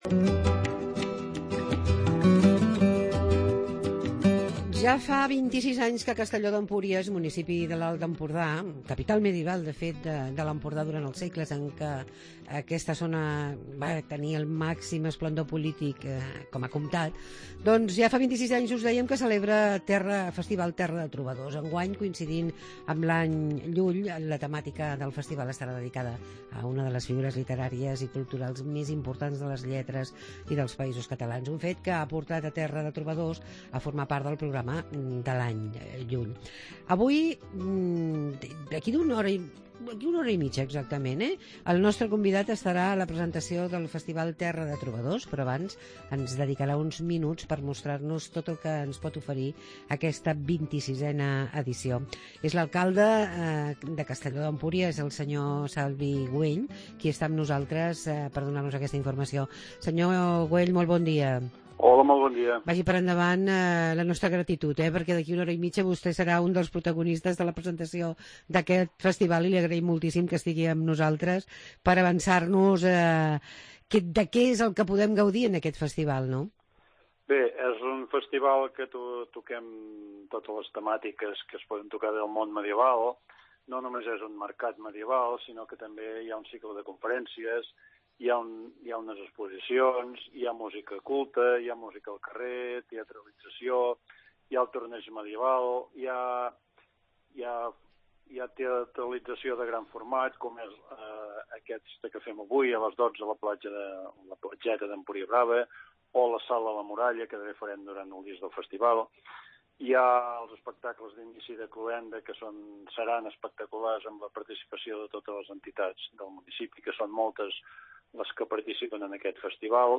Entrevista amb L'alcalde de Castelló d'Empùries que ens parla del Festival "Terra de Trobadors"